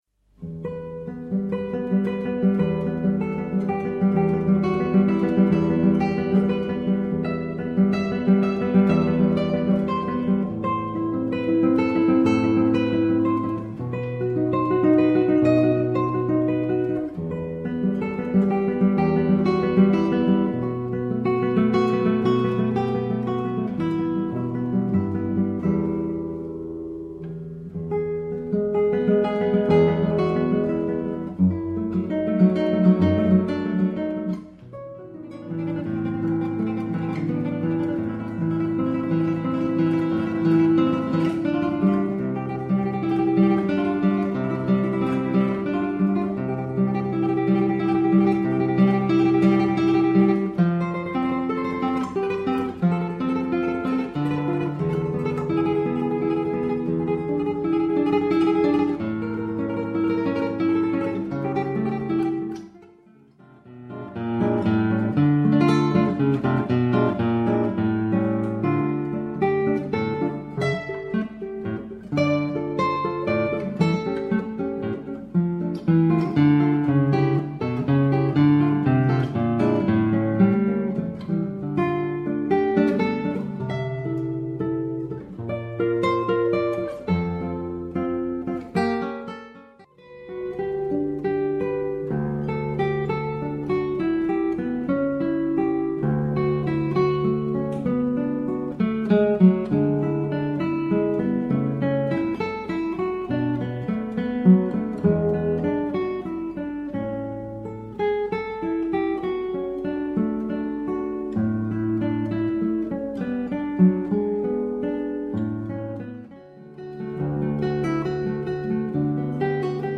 Classical Crossover Guitarist in Scotland